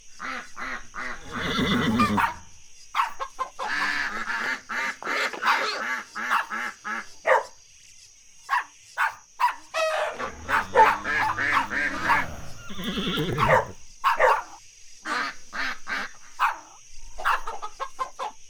Farm_Loop_01.wav